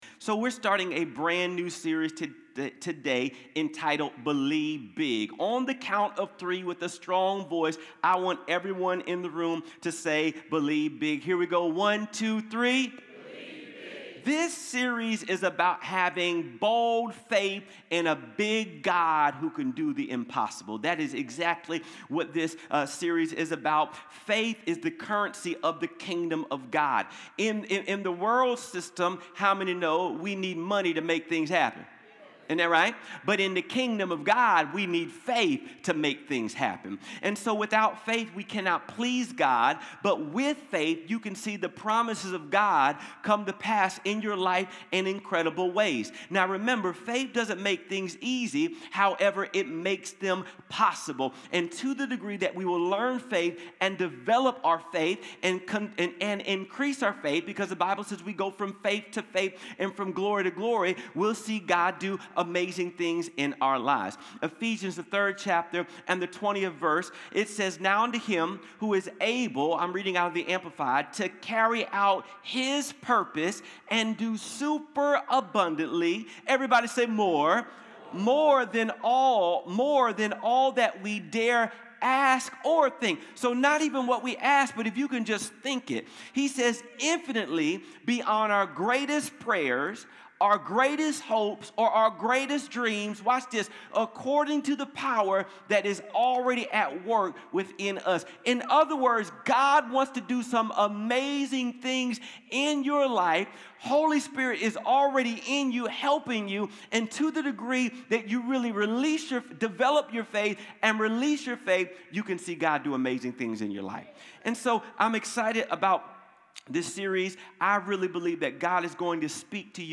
Sunday Service 1.mp3